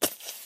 1.21.4 / assets / minecraft / sounds / mob / creeper / say3.ogg